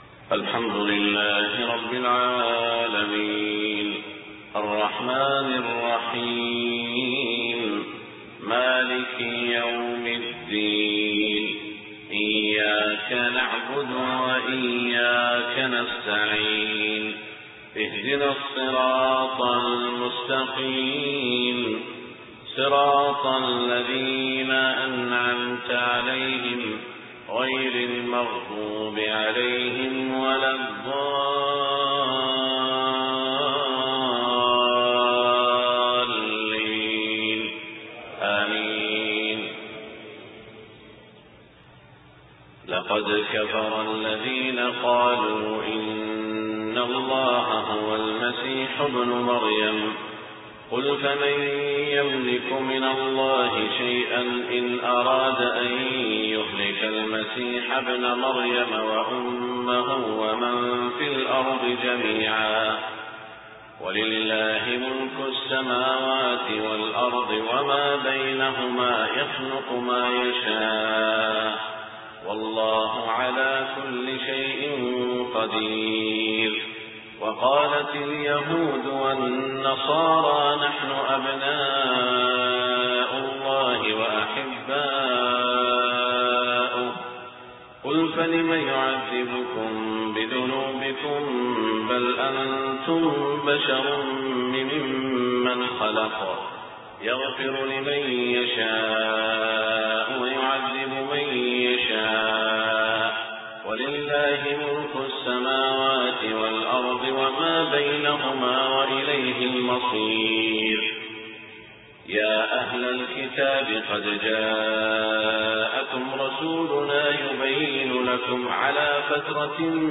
صلاة الفجر 12 صفر 1429هـ من سورة المائدة > 1429 🕋 > الفروض - تلاوات الحرمين